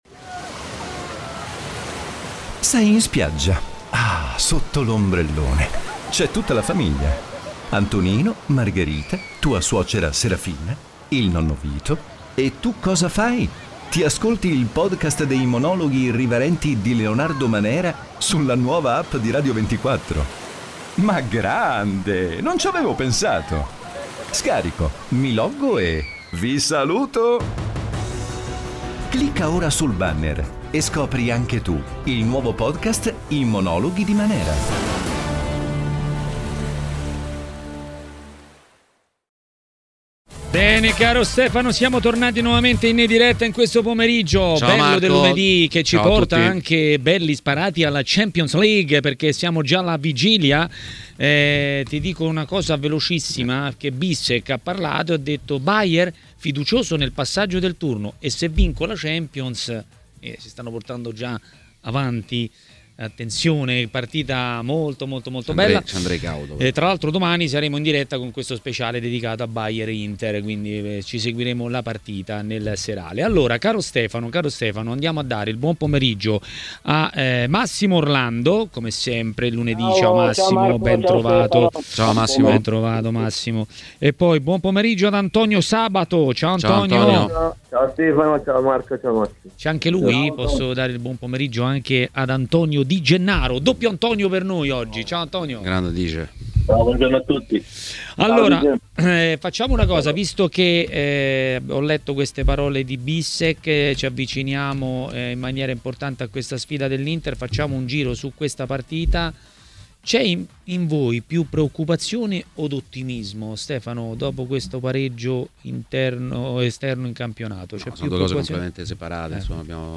A TMW Radio, durante Maracanà, è arrivato il momento di Massimo Orlando, ex calciatore.